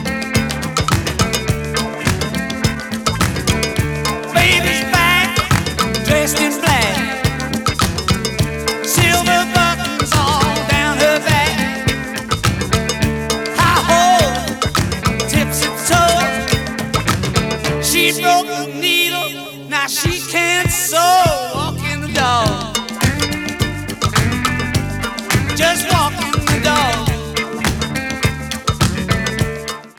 Master Tape